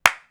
Claps
CLAP.3.NEPT.wav